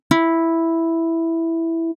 • 観測点での音
3節の純粋な速度波形に比べると、共鳴によって周波数特性が変化しギターとしての色付けがなされています。